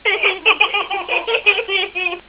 laughters.wav